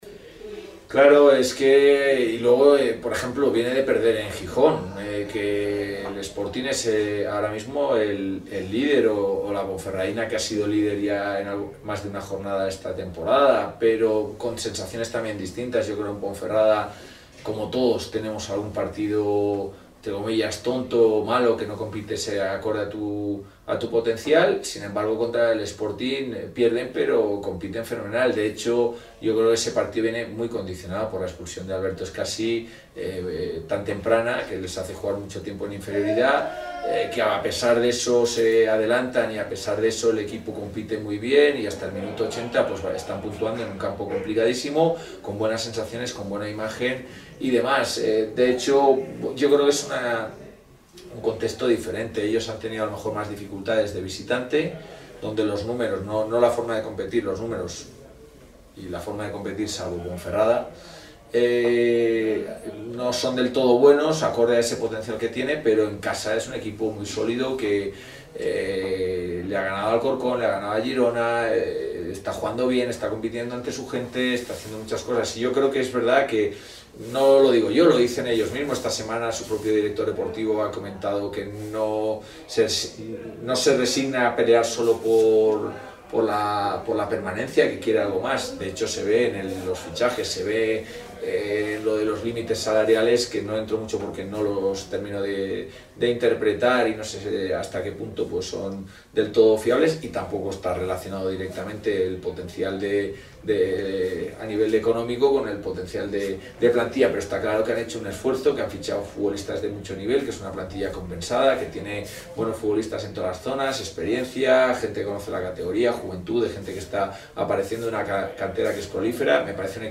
en la rueda de prensa previa al encuentro frente al Málaga